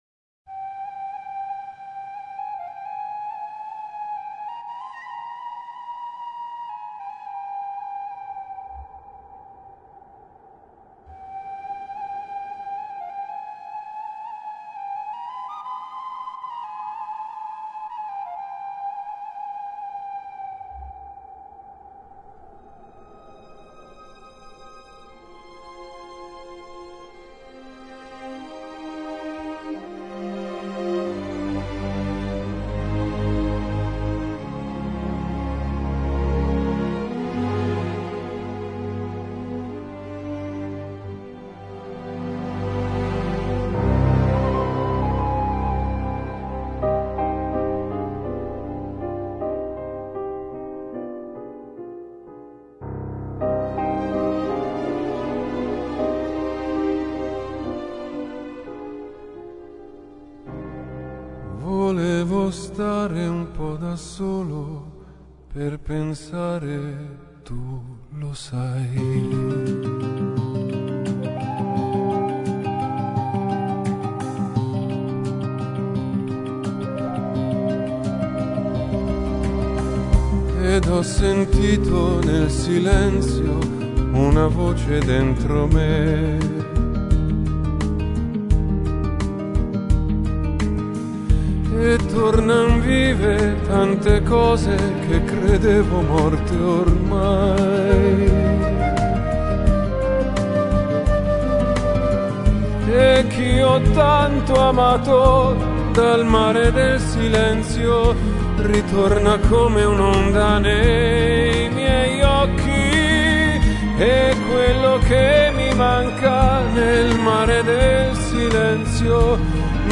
Classicas